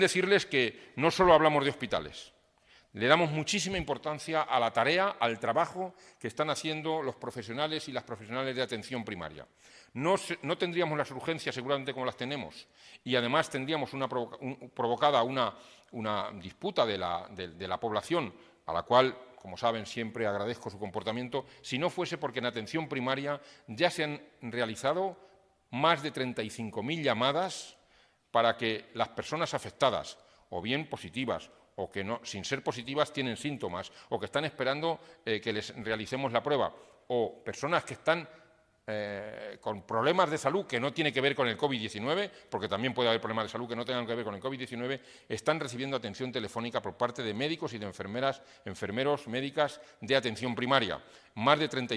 Un total de 63 pacientes afectados por Covid-19 han sido ya extubados en las unidades de críticos de los hospitales de Castilla-La Mancha desde que se inició la emergencia sanitaria, tal y como ha informado hoy el consejero de Sanidad, Jesús Fernández Sanz, durante la rueda de prensa ofrecida esta mañana.